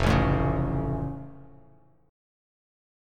F+ Chord
Listen to F+ strummed